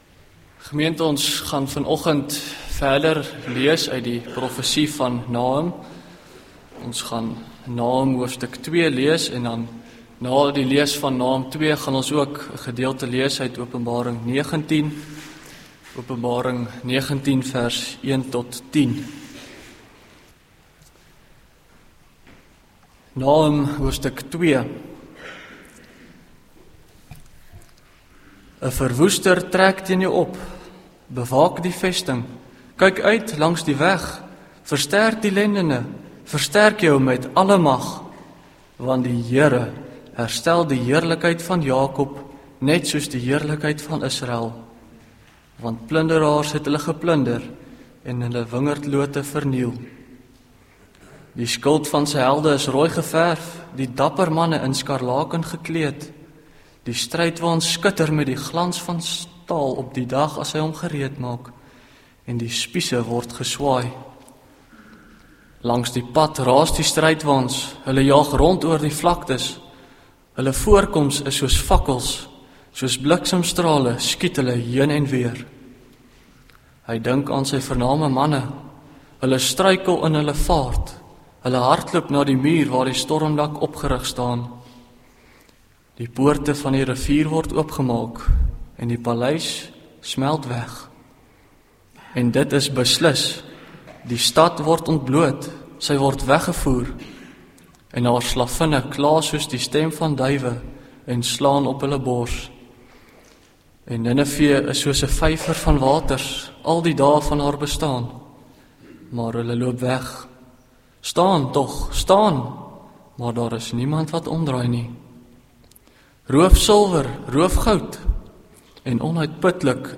Link Preek Inhoud